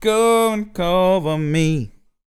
Blues Soul